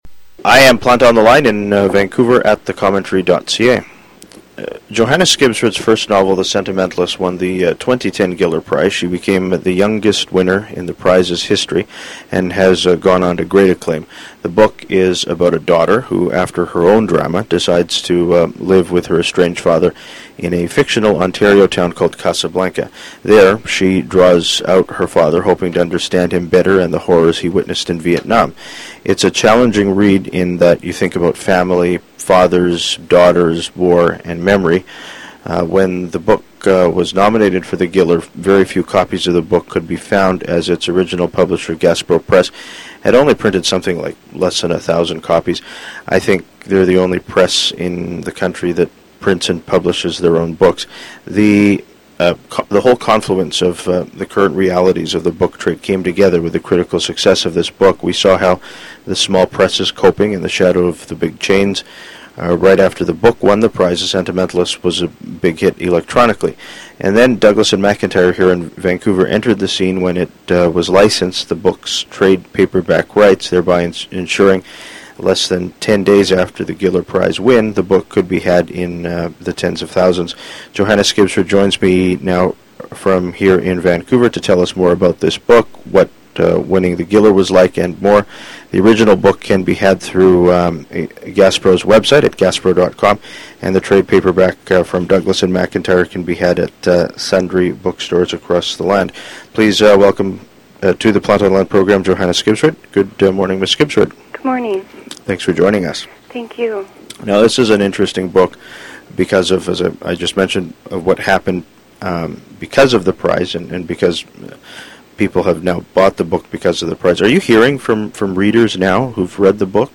Johanna Skibsrud joins me now from here in Vancouver to tell us more about this book, what winning the Giller was like, and more.